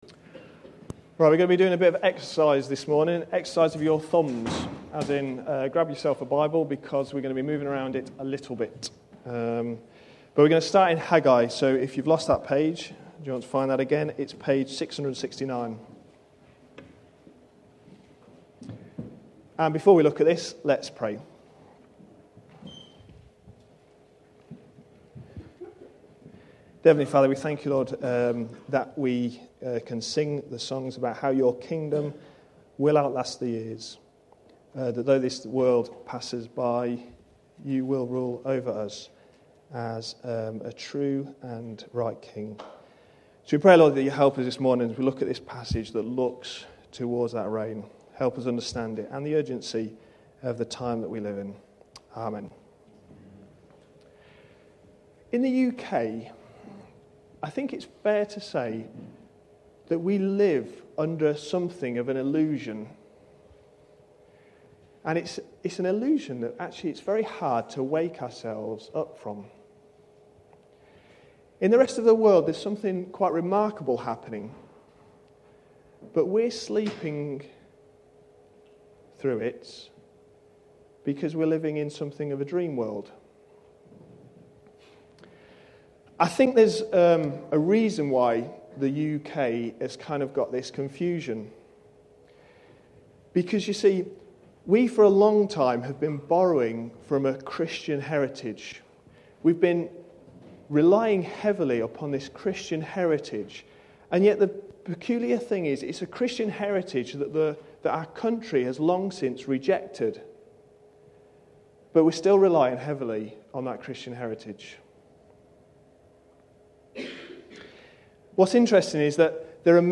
A sermon preached on 12th June, 2011.